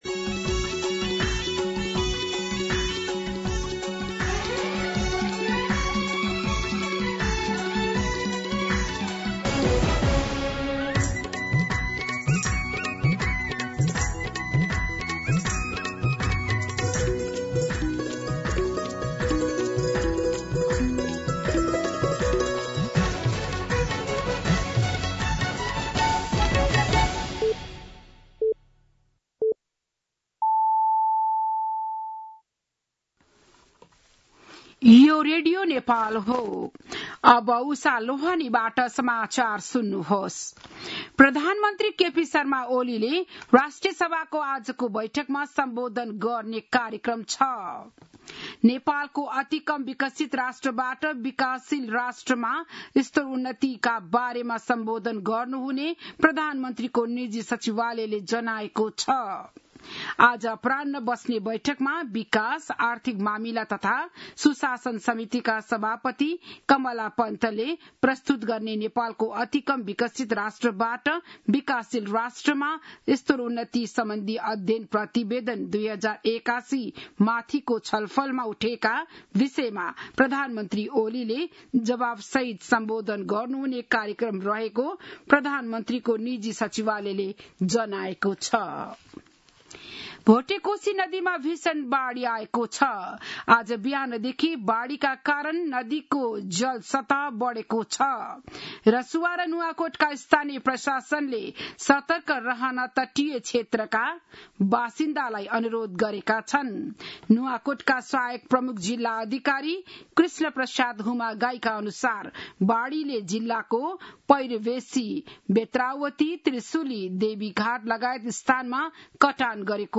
बिहान ११ बजेको नेपाली समाचार : १४ साउन , २०८२